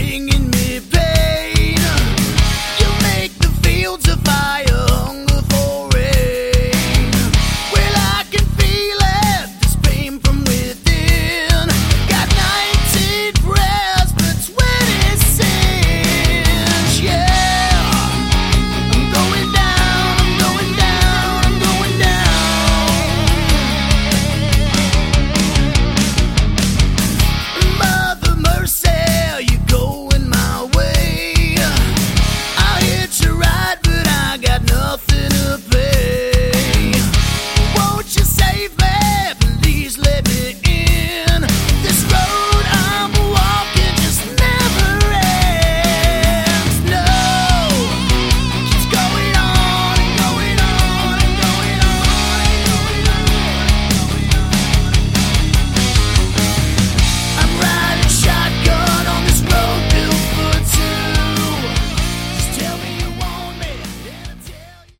Category: Melodic Hard Rock
Lead Vocals, Bass, 12 String Guitar
Drums, Percussion, Backing Vocals
Guitars, Background Vocals